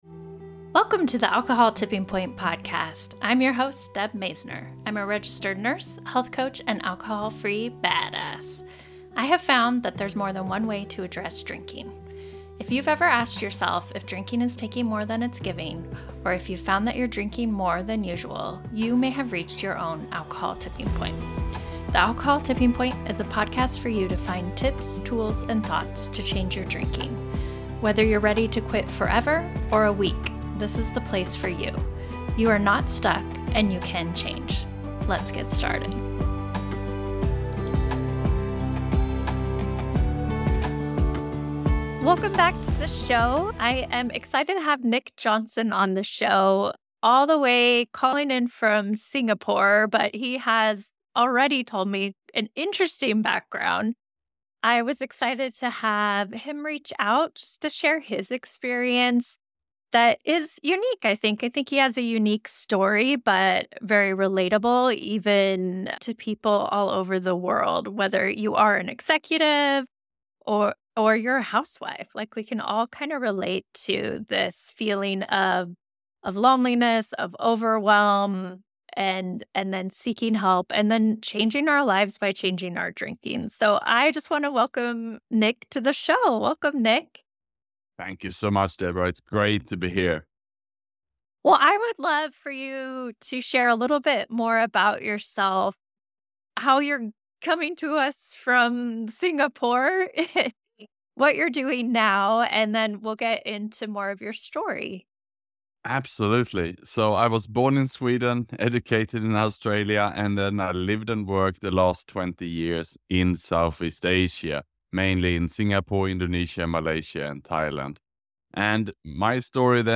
Sober in Singapore: How A Lonely Executive Quit Drinking and Turned His Life Around. Interview